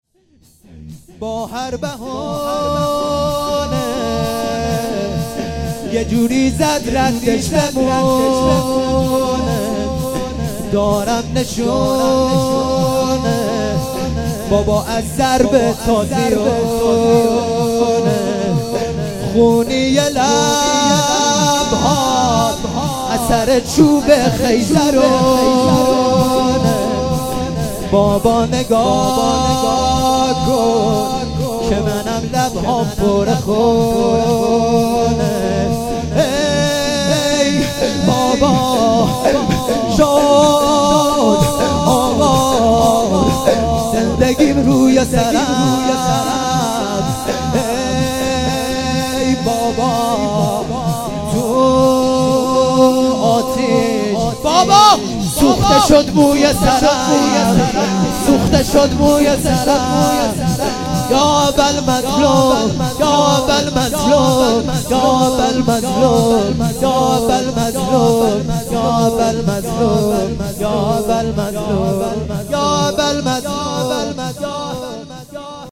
لطمه زنی شب سوم محرم